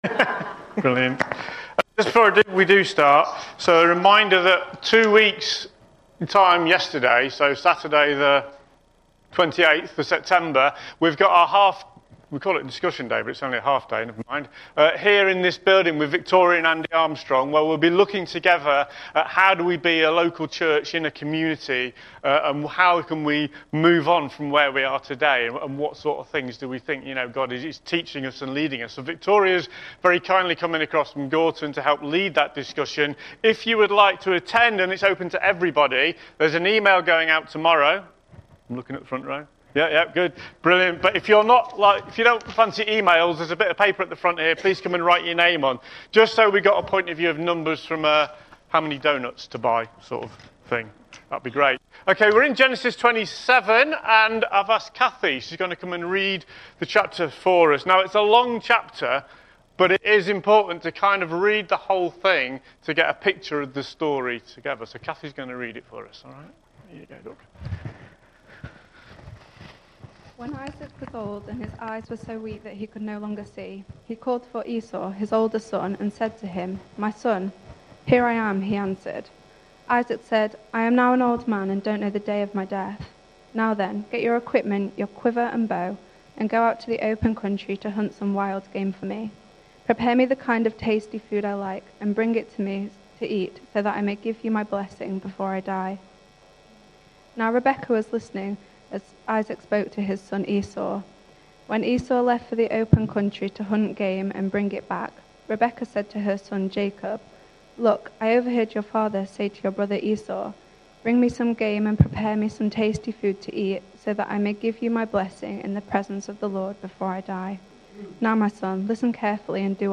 Sermon Summary: God’s Unwavering Promises Scripture References: Genesis 27 Key Themes: God’s sovereignty, His faithfulness to promises, the importance of pursuing His calling, and the availability of His mercy. Summary: The sermon begins with a reflection on the tumultuous family dynamics depicted in Genesis 27. It highlights the contrasting favoritism between Isaac and Esau, and the deceitful actions of Jacob and Rebekah to secure the coveted blessing.